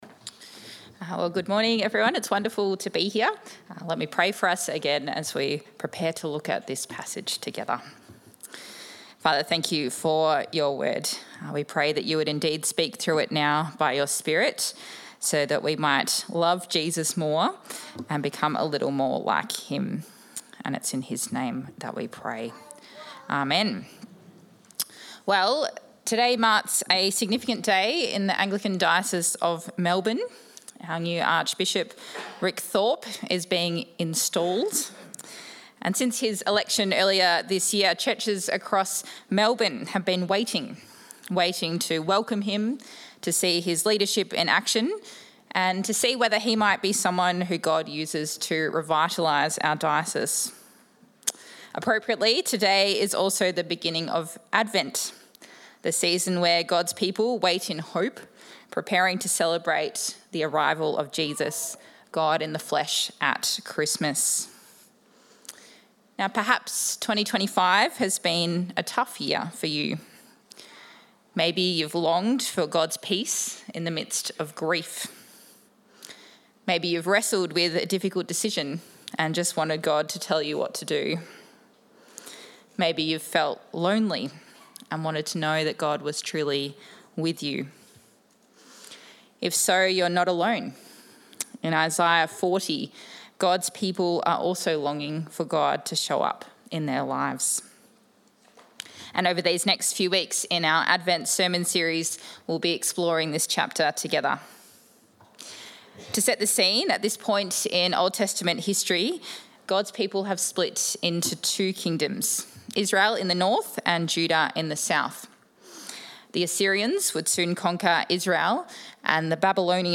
Bible Passage